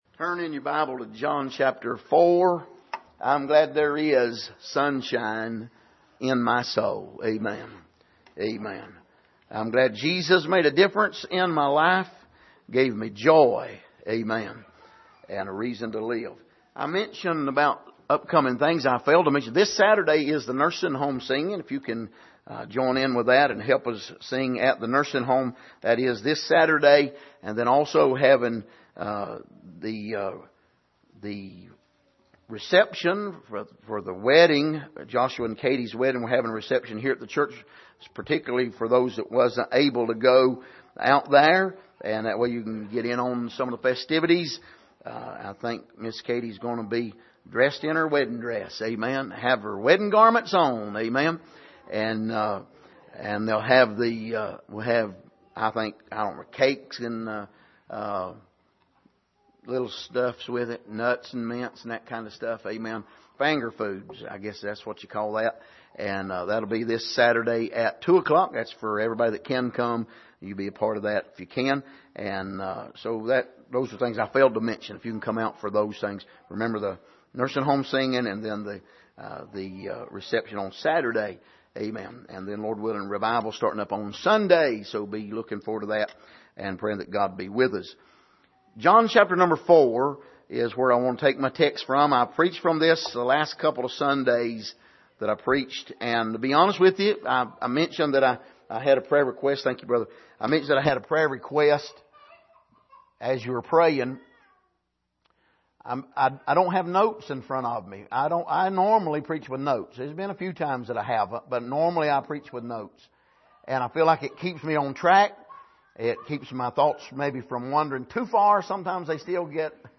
Passage: John 4:27-32 Service: Sunday Morning